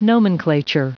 Prononciation audio / Fichier audio de NOMENCLATURE en anglais
Prononciation du mot nomenclature en anglais (fichier audio)